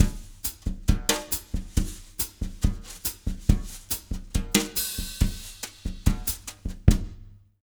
140BOSSA07-L.wav